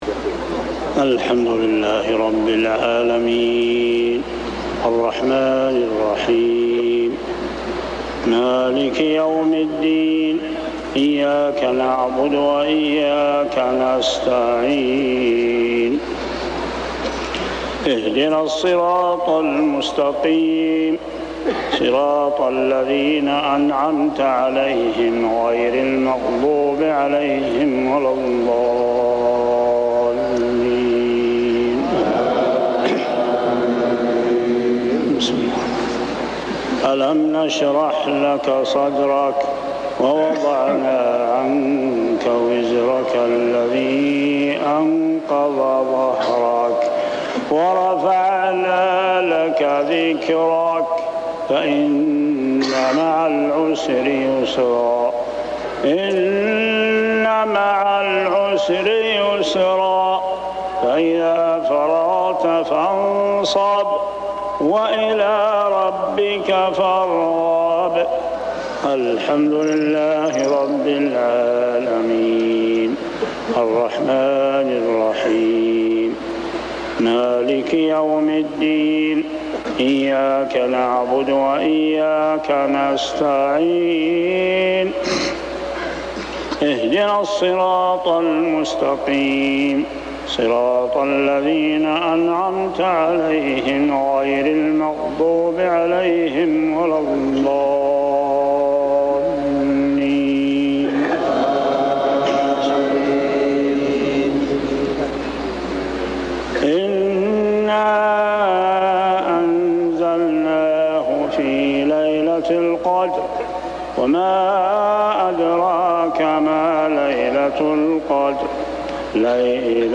صلاة المغرب 5-9-1402هـ سورتي الشرح و القدر | Maghrib prayer Surah Ash-Sharh and Al-qadr > 1402 🕋 > الفروض - تلاوات الحرمين